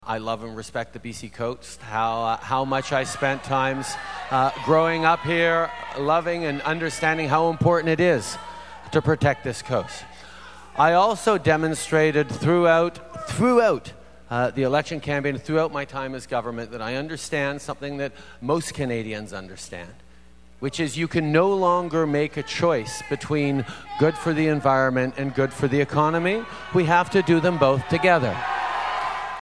Four noisy, disruptive protestors had to be removed from the town hall in Nanaimo where the Prime Minister was speaking.
They were upset about the federal government’s approval of the Kinder Morgan pipeline and continued to yell out while Justin Trudeau tried to answer questions about the federal government’s approval of the project….